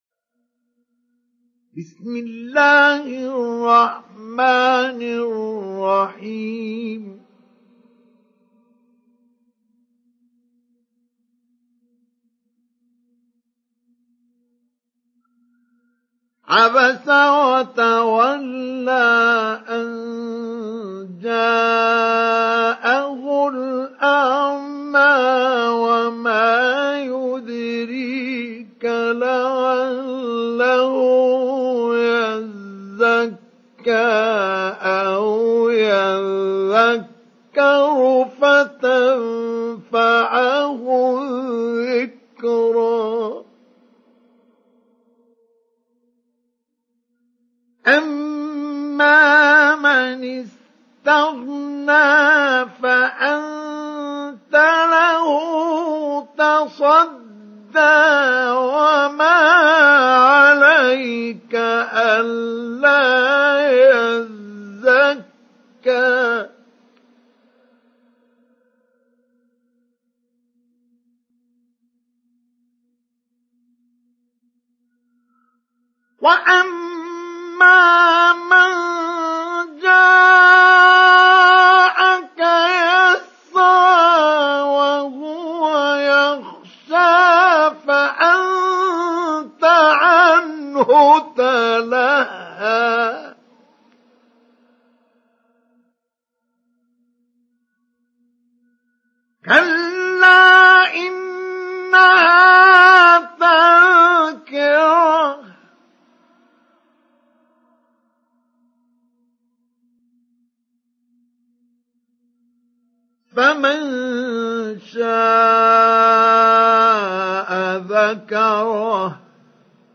Download Surat Abasa Mustafa Ismail Mujawwad